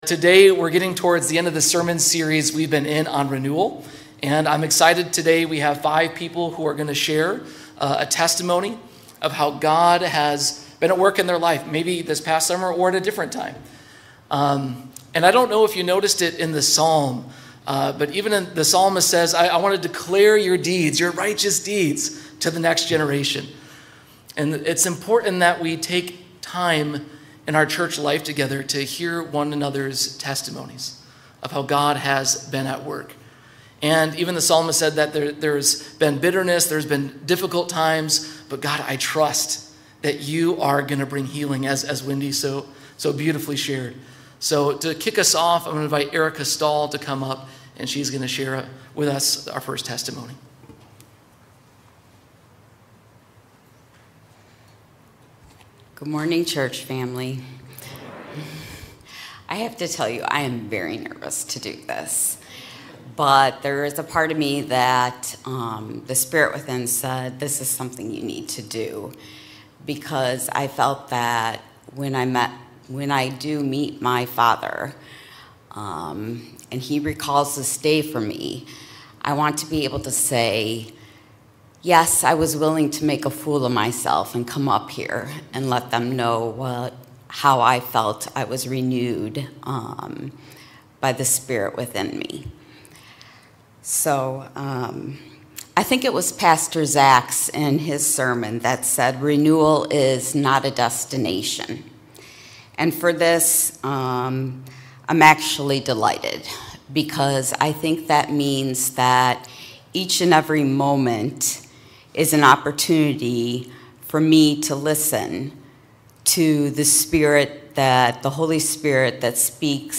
Sermons | Faith Covenant Church
Congregational Reflections